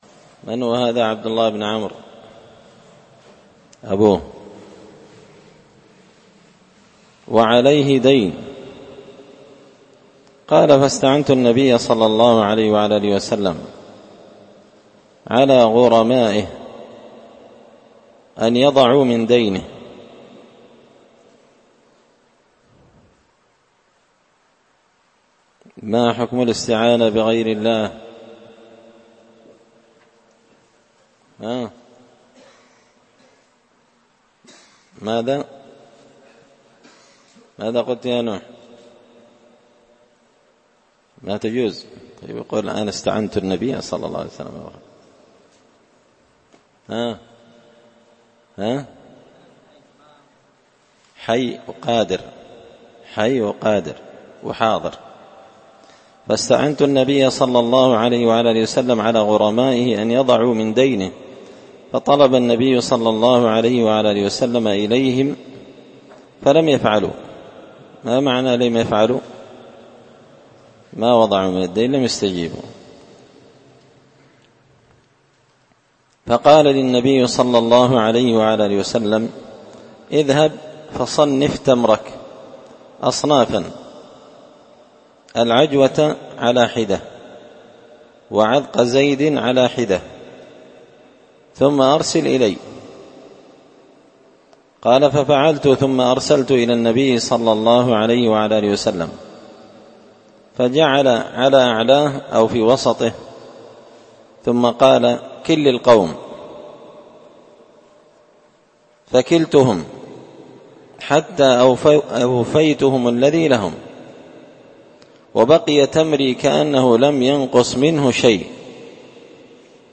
الدرس 126 فصل من عجائب قدرة الله الخارقة للعادة
دار الحديث بمسجد الفرقان ـ قشن ـ المهرة ـ اليمن